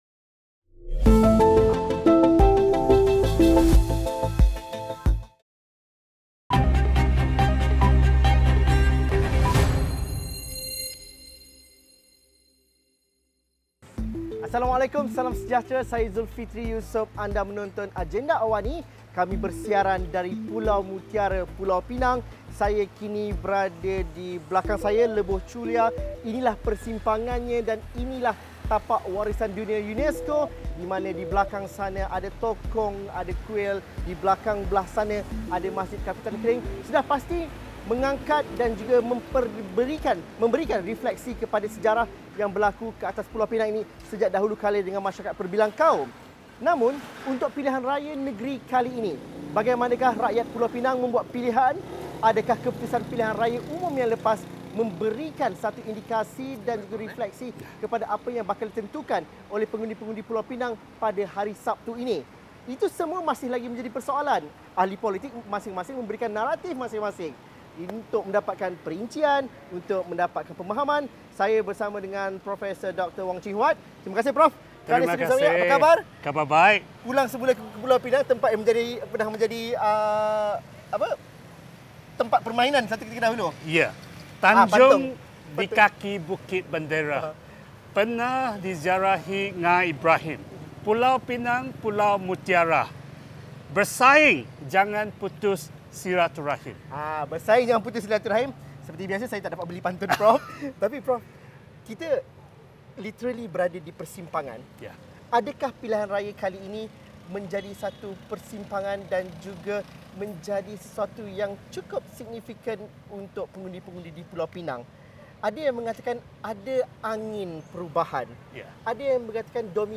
Selain isu pemilihan calon, projek tambak laut dan asrama penempatan warga asing antara isu lokal yang jadi modal kempen parti politik bertanding di kerusi panas N.38 Bayan Lepas. Temu bual bersama calon PH dan PN bersama penganalisis politik dalam Agenda Awani 8.30 malam.